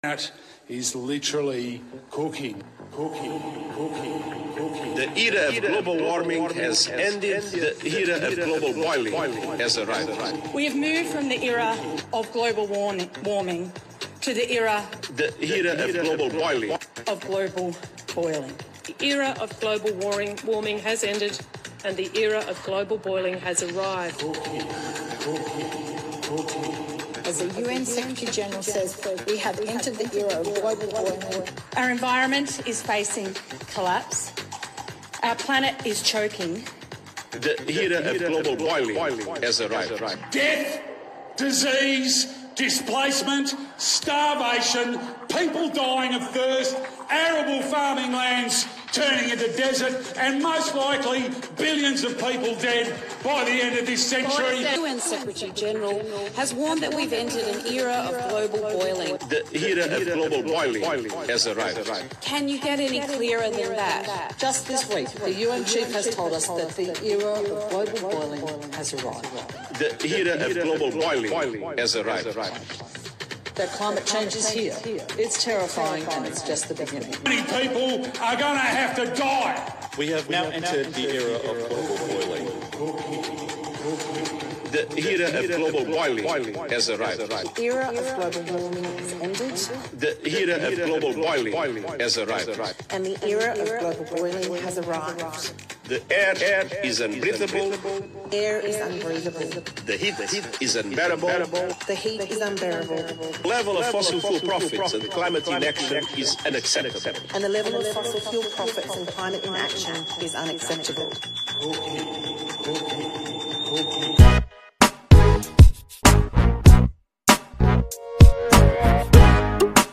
Lenard Nimoy was the Star Trek actor that narrated the ice age nonsense.